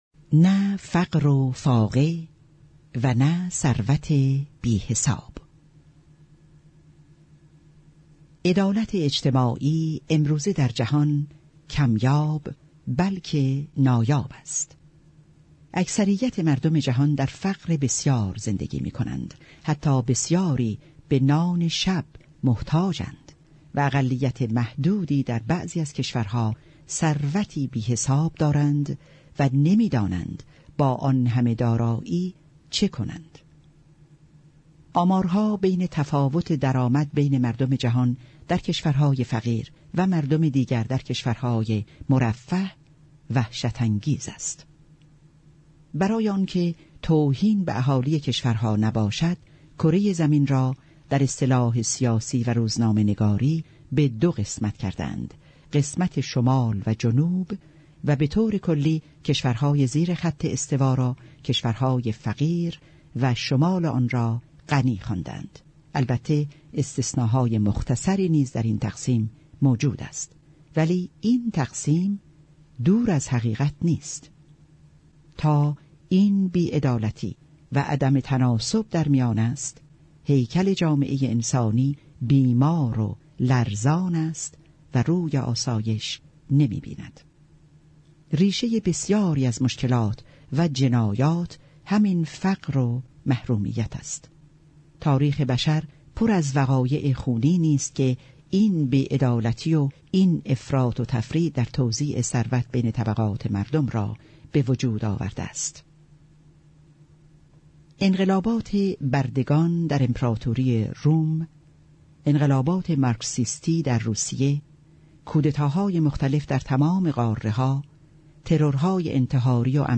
کتاب صوتی در شناسائی آئین بهائی | تعالیم و عقاید آئین بهائی